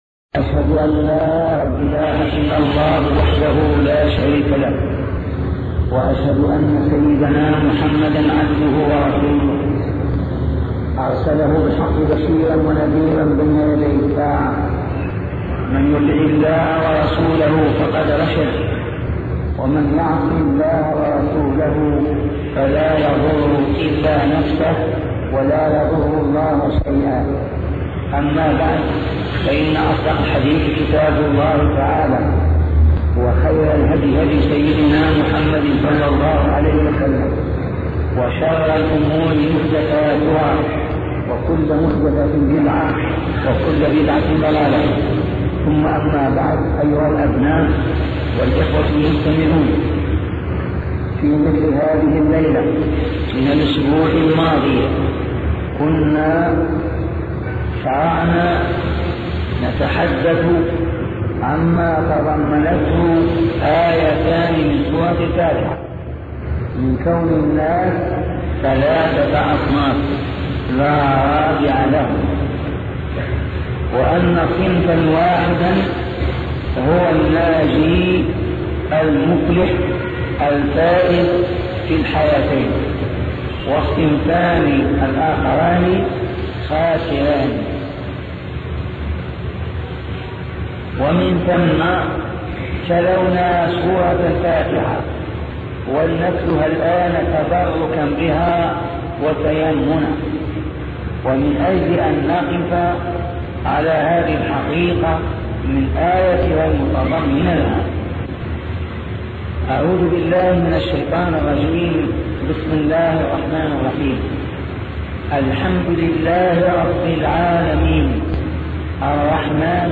شبكة المعرفة الإسلامية | الدروس | نعم الله على المؤمنين |أبوبكر الجزائري